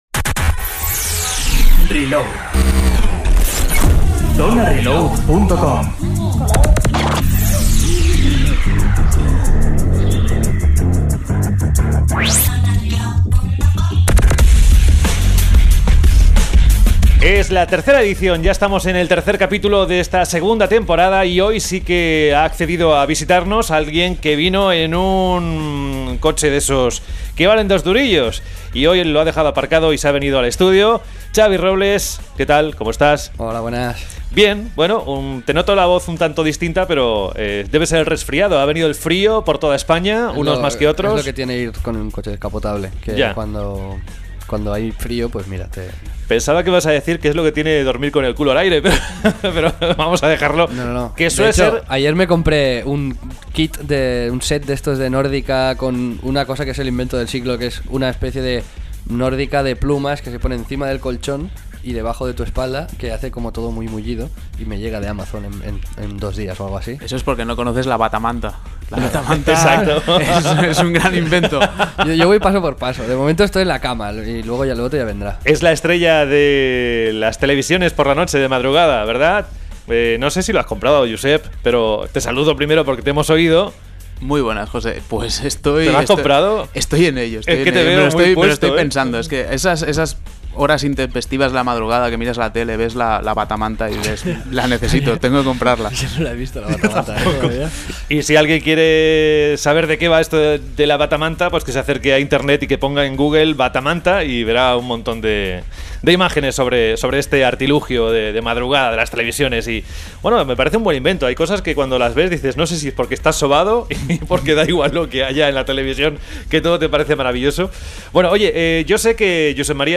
Reload se graba en los estudios de Scanner FM , una radio alternativa que seguro que os gustará y que podéis escuchar por internet. Guión del programa En una semana en la que varios miembros de la redacción han jugado sin parar al modo multijugador de Medal of Honor nos ponemos a discutir sobre la situación del género en esta faceta.